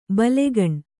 ♪ balegan